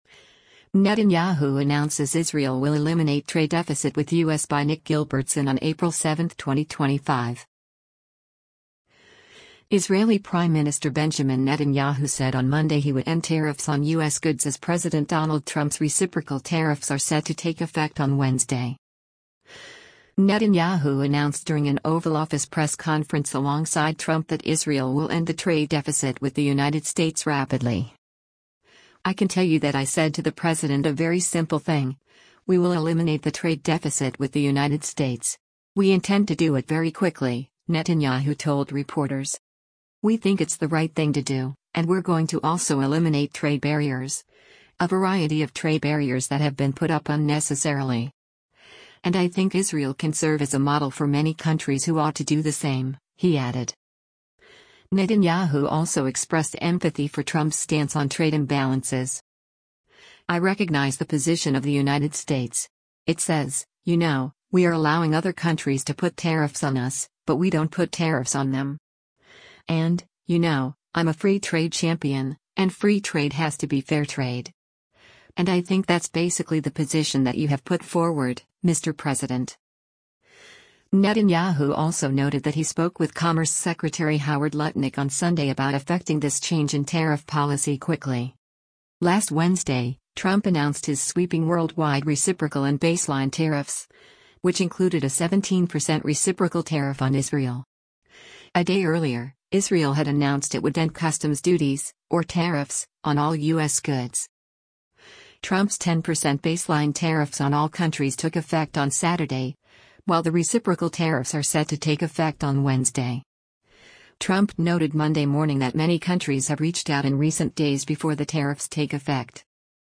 Netanyahu announced during an Oval Office press conference alongside Trump that Israel will end the “trade deficit with the United States” rapidly.